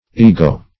ygo - definition of ygo - synonyms, pronunciation, spelling from Free Dictionary Search Result for " ygo" : The Collaborative International Dictionary of English v.0.48: Ygo \Y*go"\, obs. p. p. of Go .